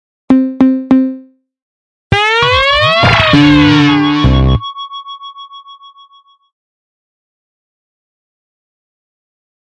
这是一个奇怪的电子效应loog，是由Waldorf Attack VST Drum Synth创建的。
Tag: 循环 怪异 电子 ConstructionKit 舞蹈 120BPM 科幻 有节奏